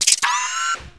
command_off.wav